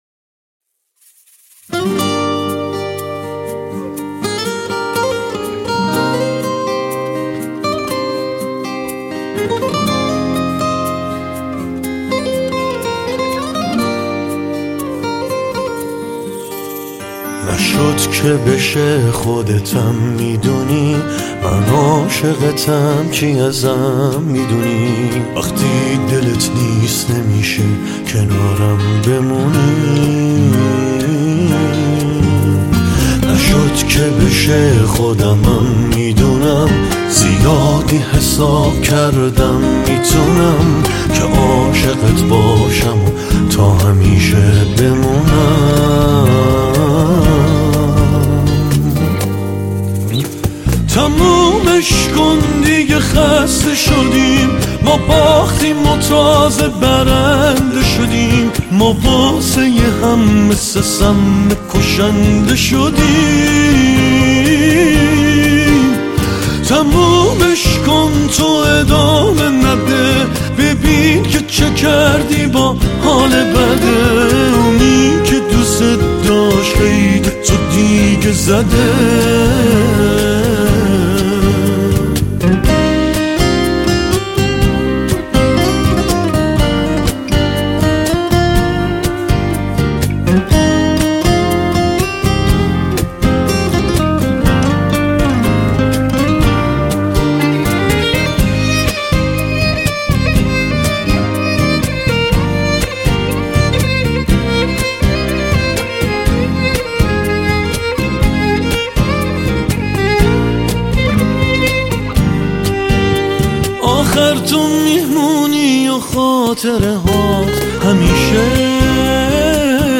(نسخه آنپلاگد)